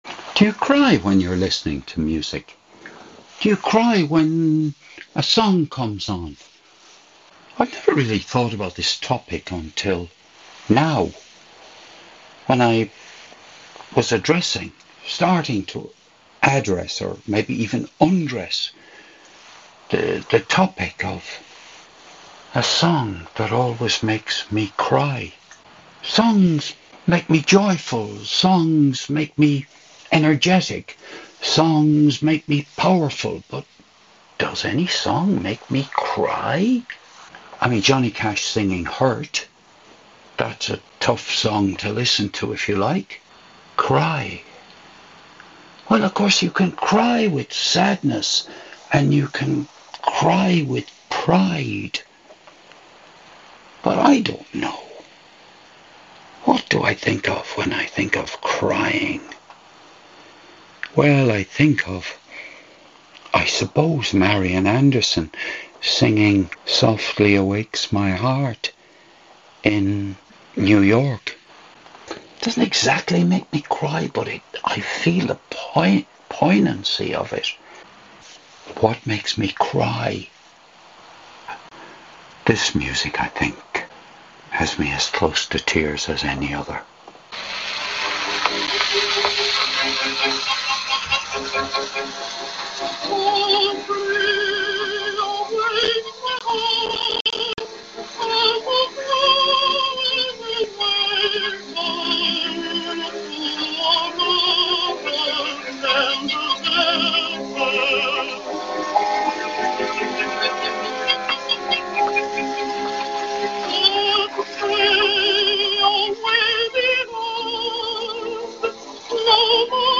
this was recorded on the morning of Thursday 13th of February 2025 - in my kitchen